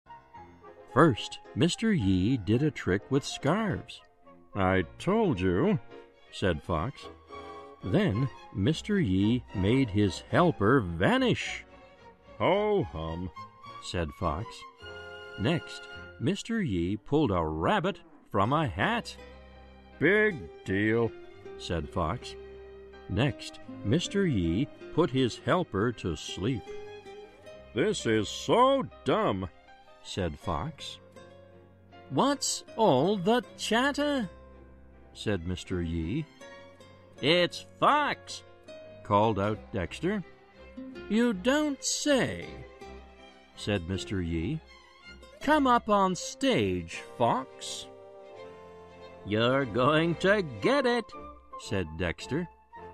在线英语听力室小狐外传 第69期:戏法的听力文件下载,《小狐外传》是双语有声读物下面的子栏目，非常适合英语学习爱好者进行细心品读。故事内容讲述了一个小男生在学校、家庭里的各种角色转换以及生活中的趣事。